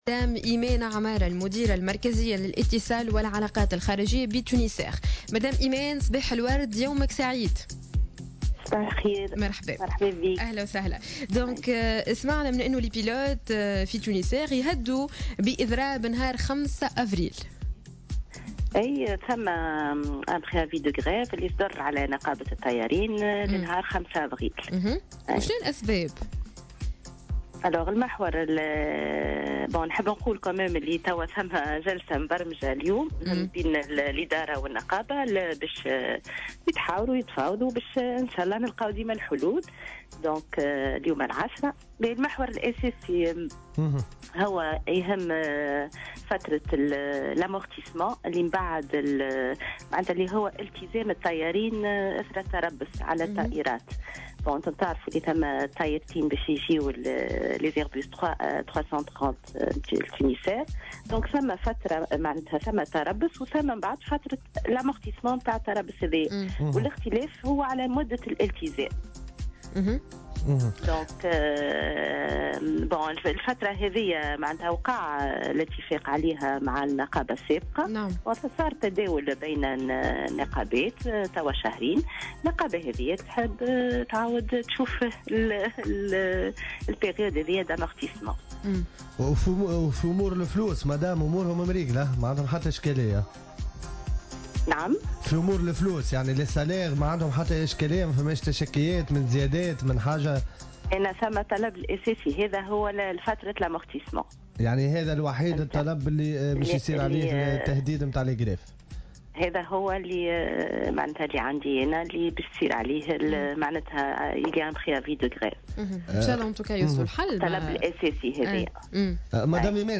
dans une intervention sur les ondes de Jawhara FM